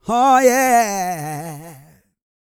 E-GOSPEL 232.wav